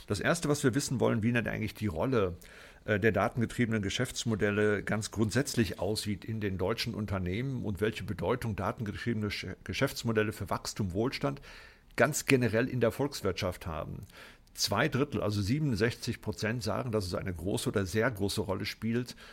Mitschnitte der Pressekonferenz
bitkom-pressekonferenz-data-economy-bedeutung-datengetriebener-geschaeftsmodelle.mp3